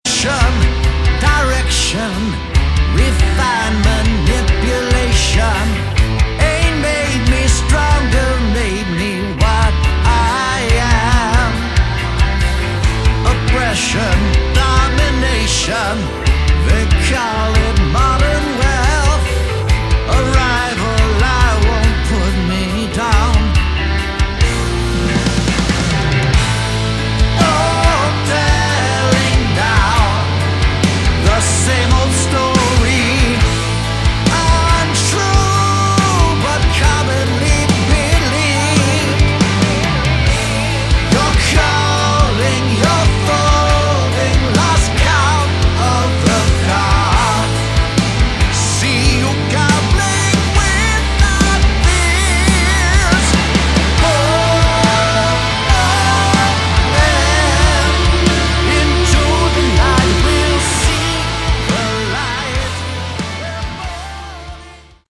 Category: Melodic Metal / Prog Metal
guitars, bass
drums
keyboards